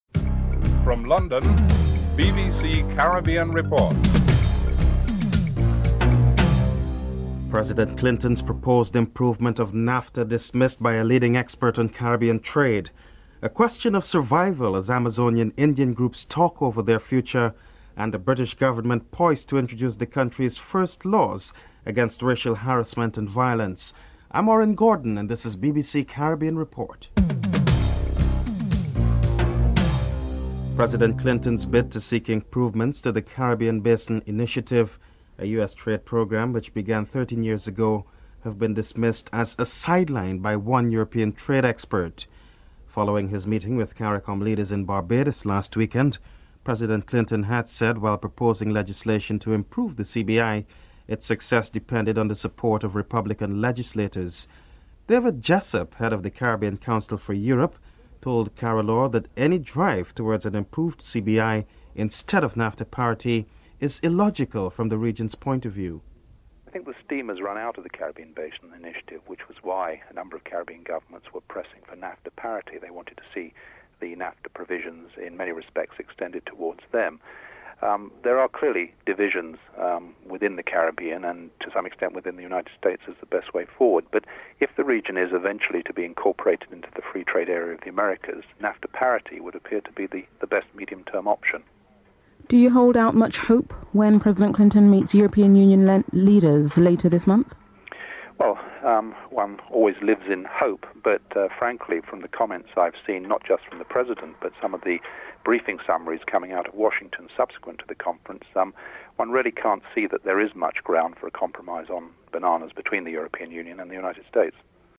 Michael O'Brien, Home Office Minister and Black MPs Bernie Grant and Diane Abbott are interviewed (10:25-14:26)